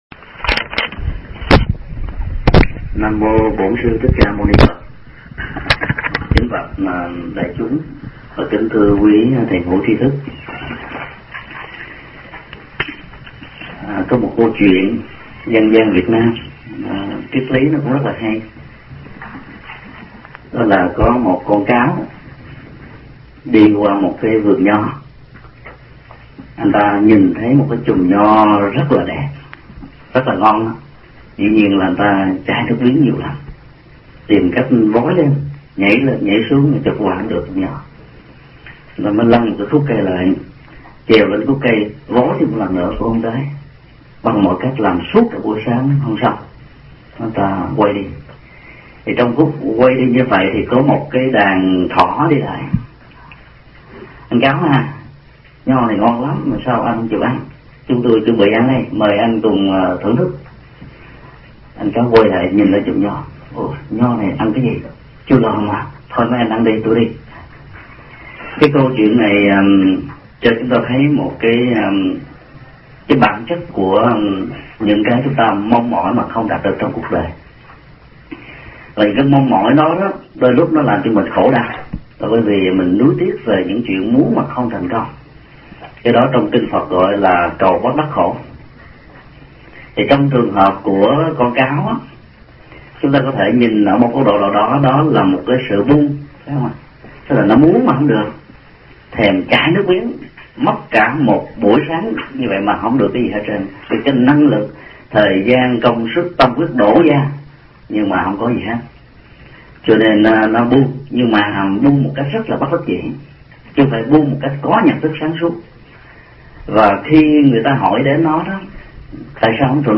Mp3 Pháp Thoại Buông xả khổ đau
giảng tại chùa Từ Quang, Dallas, ngày 3 tháng 10 năm 2004